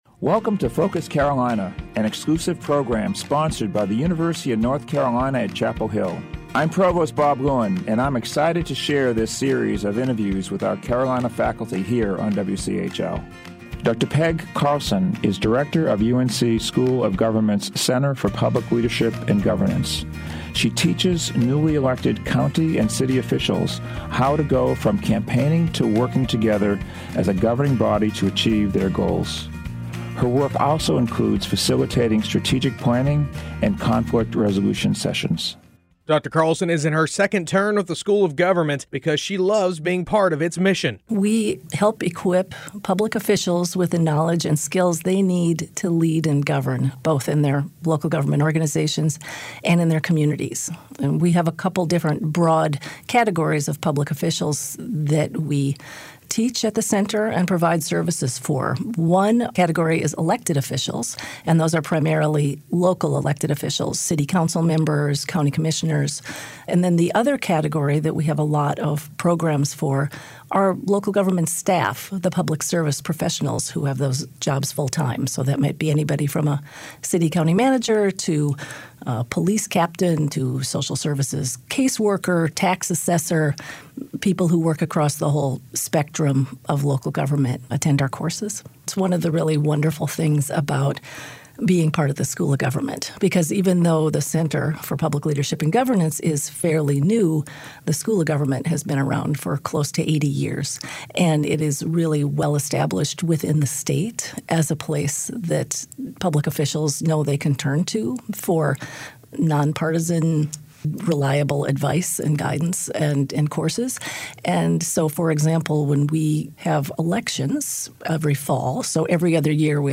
Tune in to Focus Carolina during morning, noon and evening drive times and on the weekends to hear stories from faculty members at UNC and find out what ignites their passion for their work. Focus Carolina is an exclusive program on 97.9 The Hill WCHL, sponsored by the University of North Carolina at Chapel Hill.